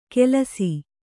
♪ kelasi